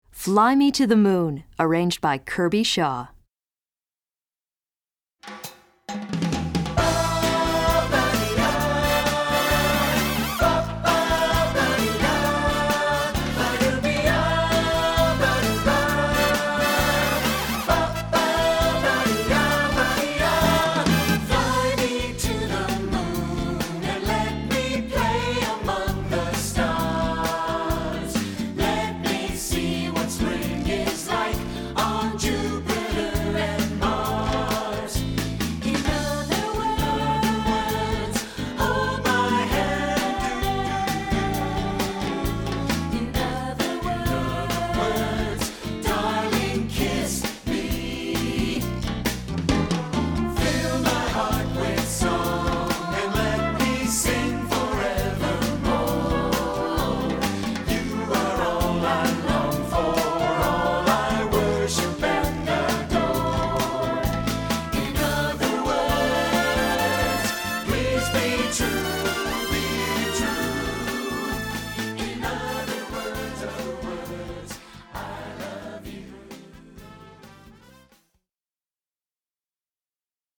Chant Mixtes